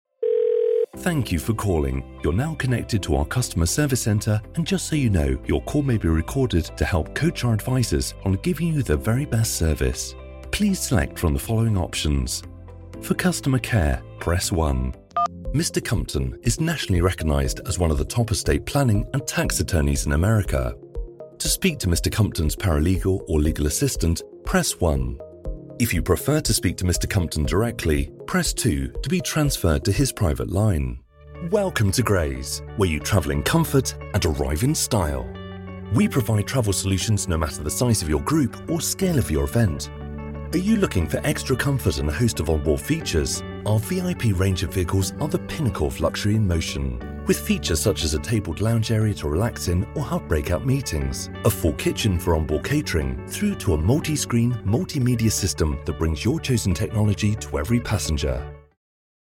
Male British Voiceover with warm, luxurious, adaptable, velvety and assured tone.
Sprechprobe: Sonstiges (Muttersprache):
I have my own professional Studio with Voiceover Booth, Neumann TLM103 microphone, Genelec Speakers plus Beyer Dynamic Headphones for monitoring.